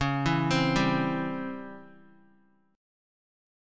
C#mM7b5 Chord